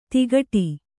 ♪ tigaṭam